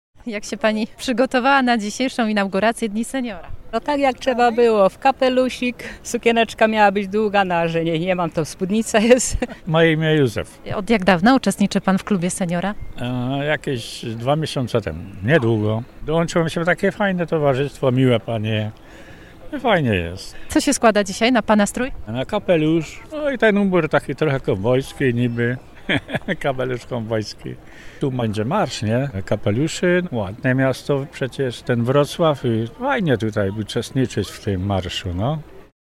02_sonda-dni-seniora.mp3